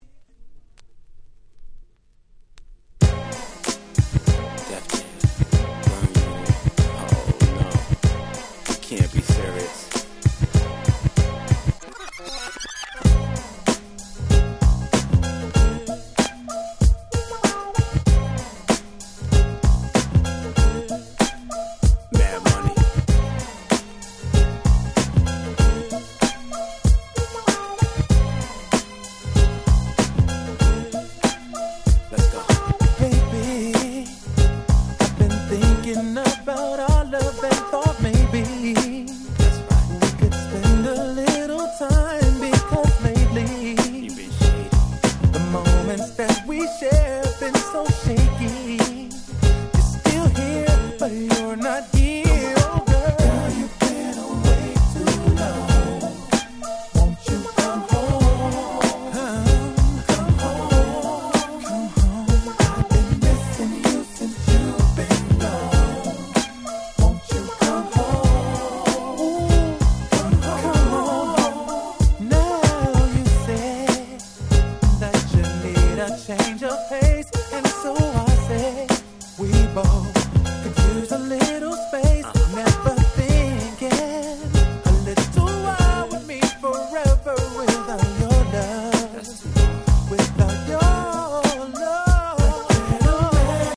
00' Big Hit R&B Album !!